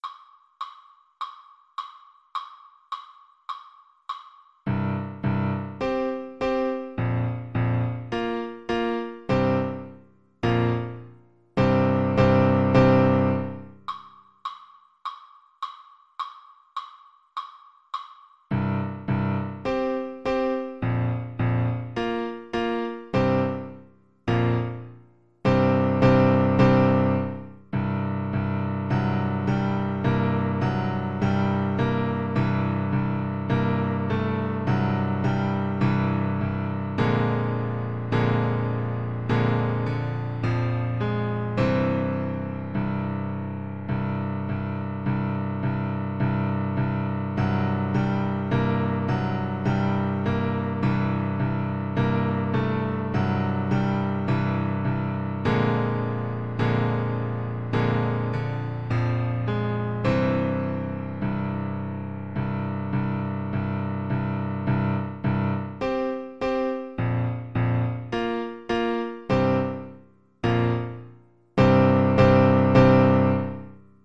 4/4 (View more 4/4 Music)
Jazz (View more Jazz Trumpet Music)